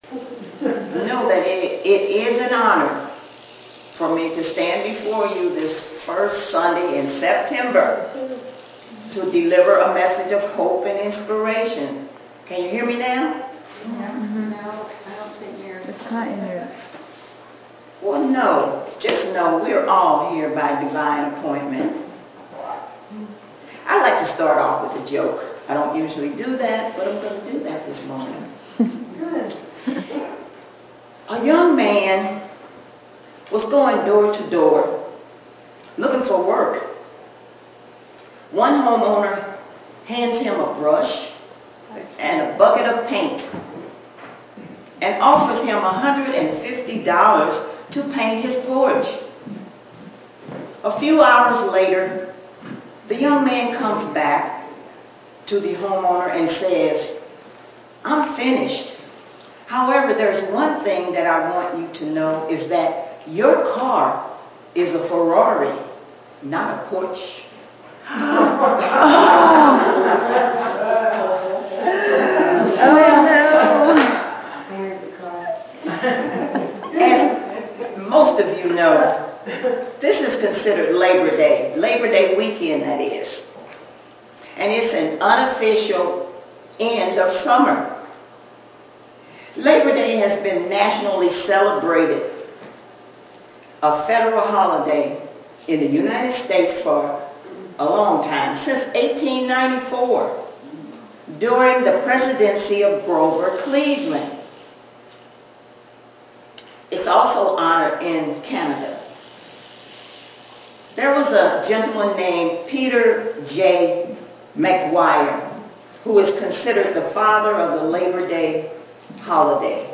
Series: Sermons 2022